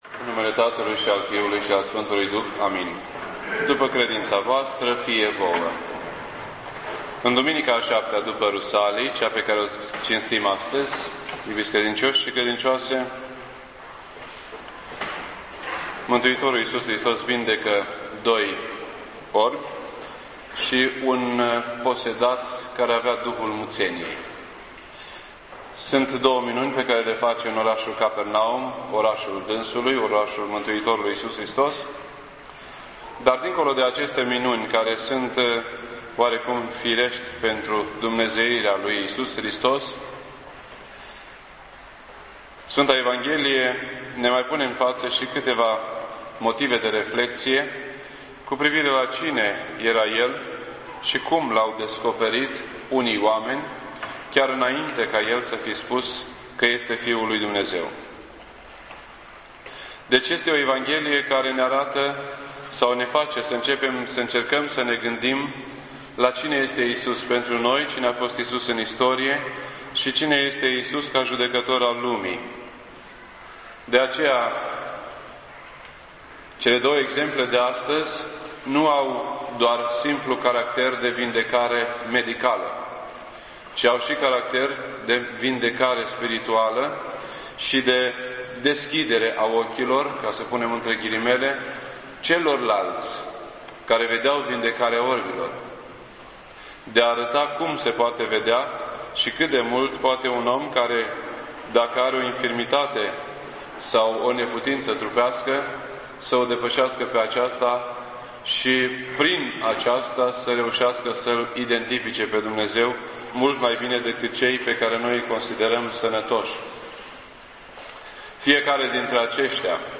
This entry was posted on Sunday, August 3rd, 2008 at 9:35 AM and is filed under Predici ortodoxe in format audio.